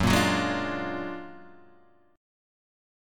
F#+M9 chord